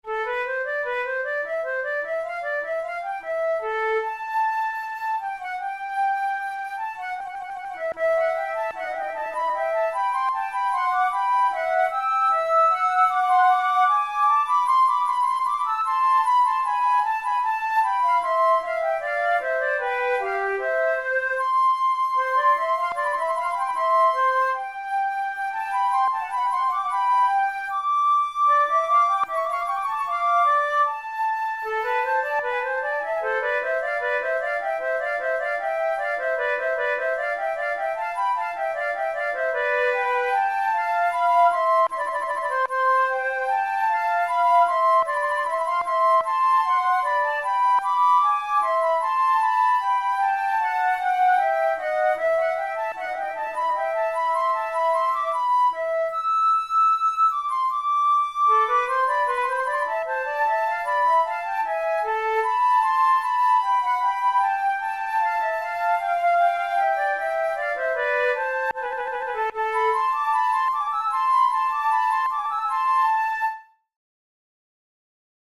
InstrumentationFlute duet
KeyA minor
Time signature2/2
Tempo76 BPM
Baroque, Sonatas, Written for Flute